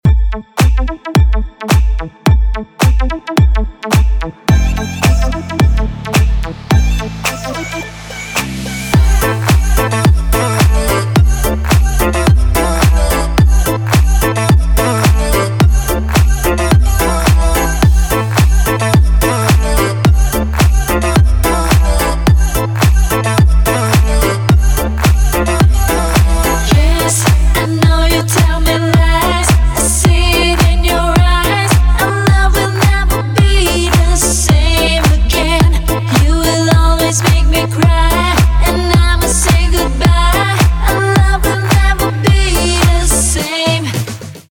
• Качество: 320, Stereo
deep house
заводные
dance